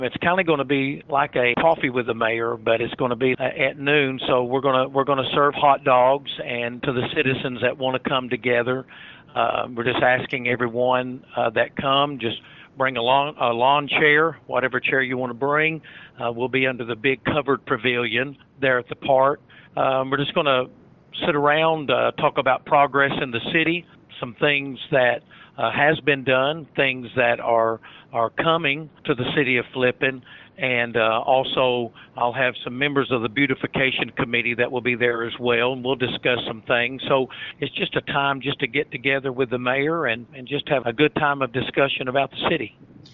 KTLO, Classic Hits and the Boot News spoke with Mayor Hogan, who gives more details about the event.